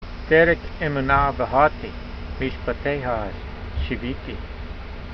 Sound (Psalm 119:30) Transliteration: de r(d)ek - emoo nah va har tee , meeshpa t ey ha shee vee tee Vocabulary Guide: I have chosen the way of truth : I have laid your judgment s before me . Translation: I have chosen the way of truth: I have laid your judgments before me .